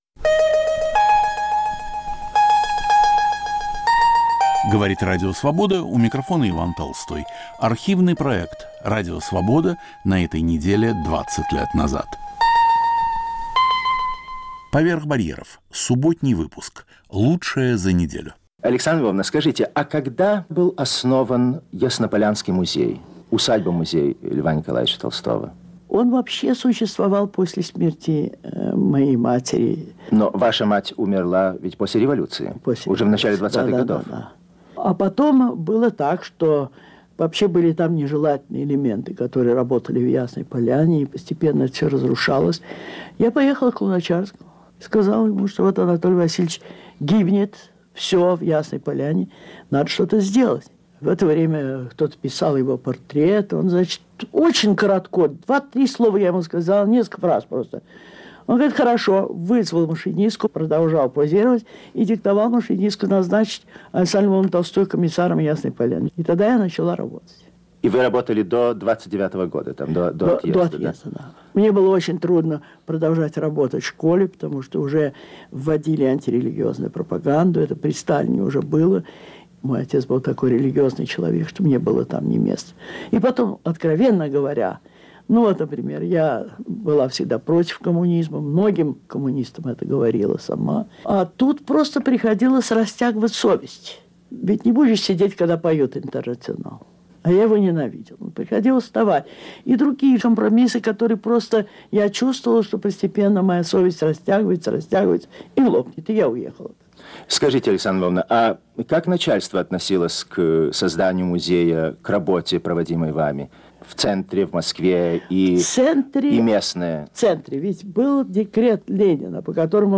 Интервью с дочерью Льва Толстого Александрой Львовной Толстой. Мои любимые пластинки: поет и рассказывает Дмитрий Пригов.